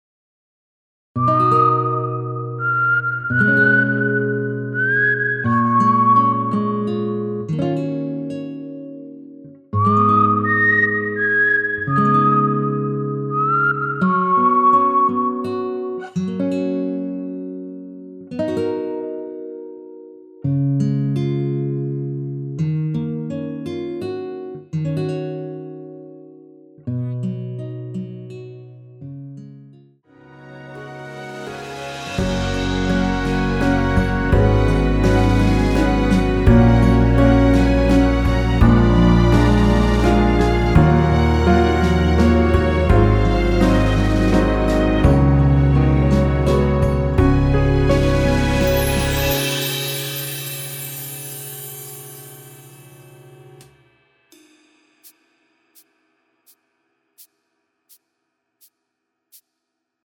무반주 구간 들어가는 부분과 박자 맞출수 있게 쉐이커로 박자 넣어 놓았습니다.(미리듣기 확인)
원키에서(-2)내린 MR입니다.
F#
앞부분30초, 뒷부분30초씩 편집해서 올려 드리고 있습니다.